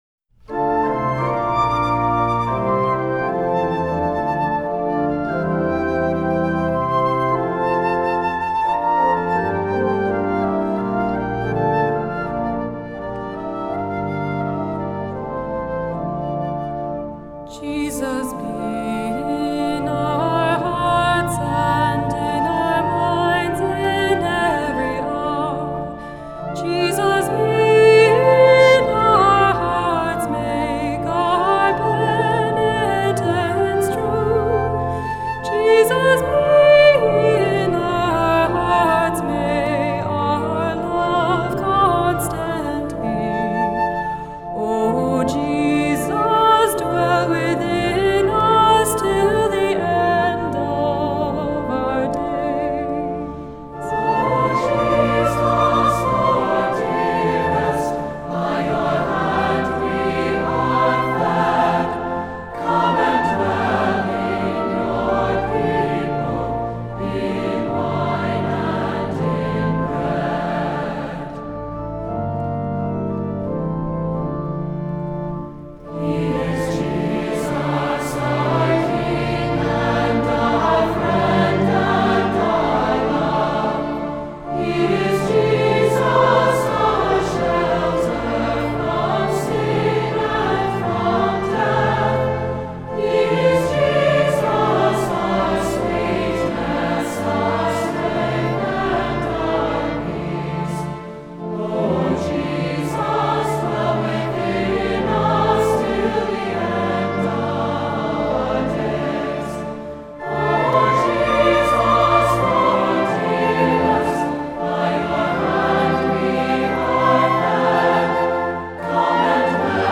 Voicing: SATB; optional Soloist